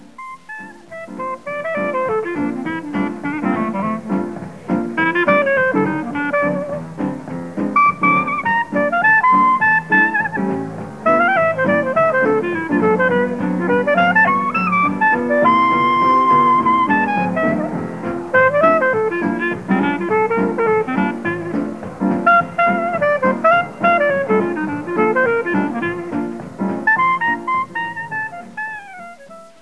Umgangsmusik
Originalaufnahme aus 1958
Jazz